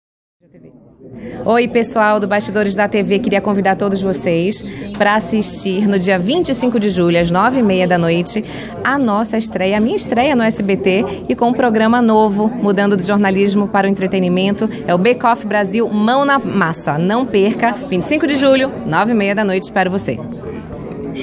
Durante a coletiva, Ticiana aproveitou para convidar os leitores do “Bastidores da TV” a acompanhar a atração, confira: